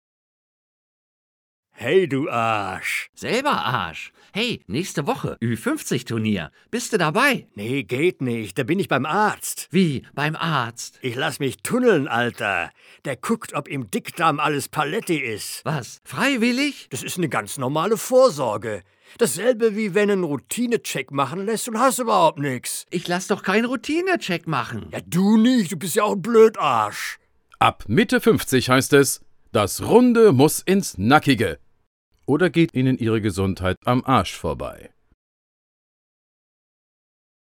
Technisch verfüge ich über alle Möglichkeiten, Sprache in guter Qualität auch spontan recorden zu können.
Werbespot Darmkrebsvorsorge (1.